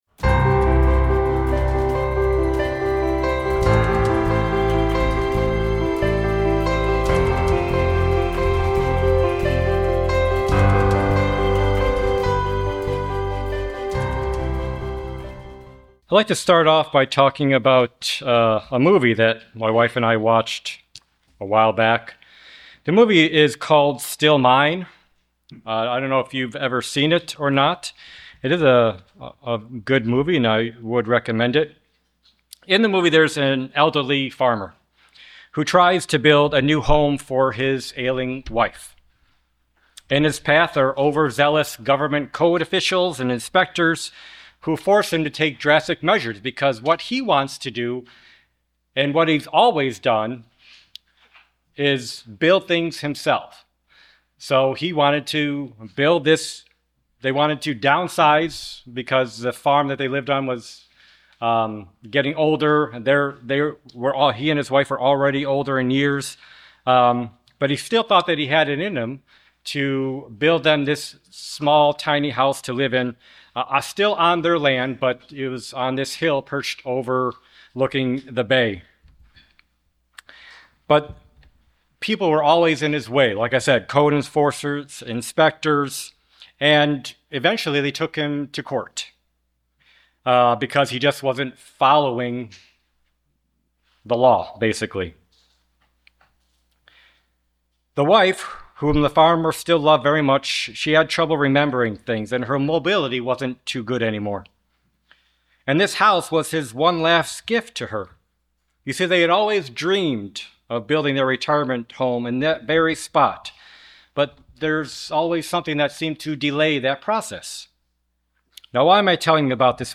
In this Sermonette, we'll examine whether our Heavenly Father marks our spiritual growth as called sons and daughters.